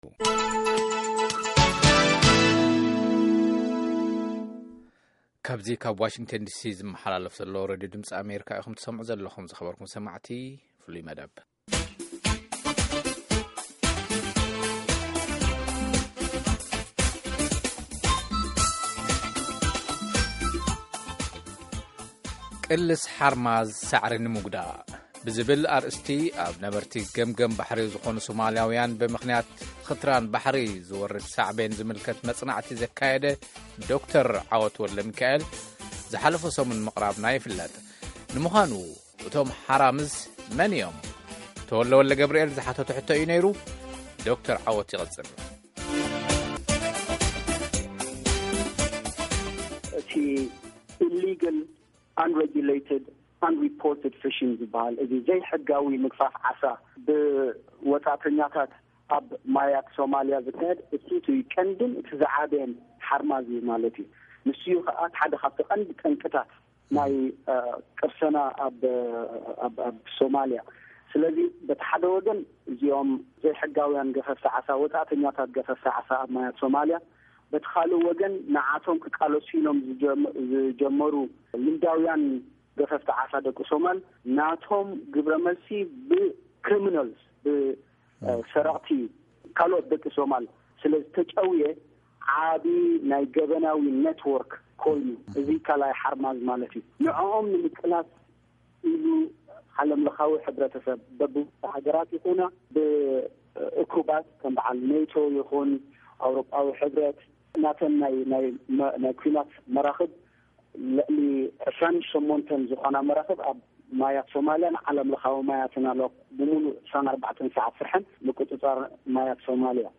ካልኣይ ክፋል ቃለ-መጠይቕ ኣብ`ዚ